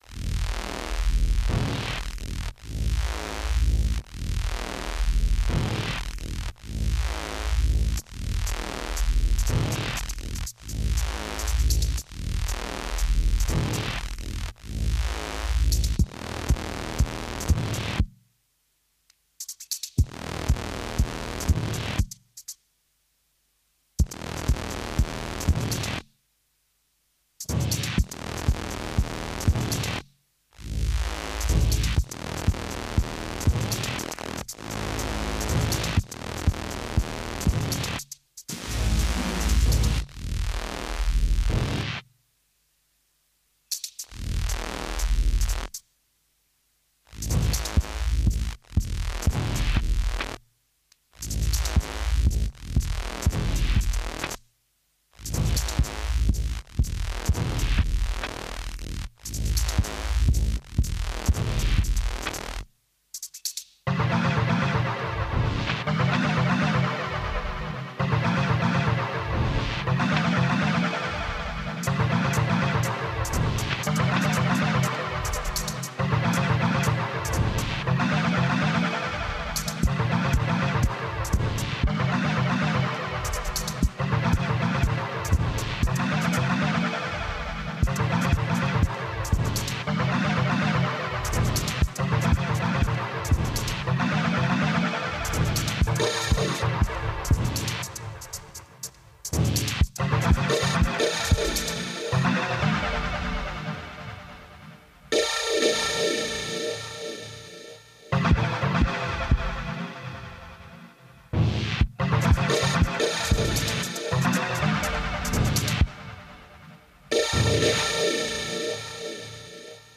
I’m down for fuzzing and falling apart.
Ambient Electronic Synth